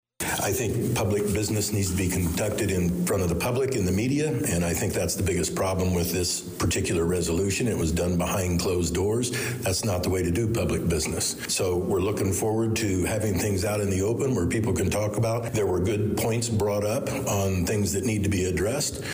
Incoming Alderman and Former Director of Public Works Doug Ahrens stated after the vote that what bothered him the most was the actual discussions about the proposal did not take place out in the open.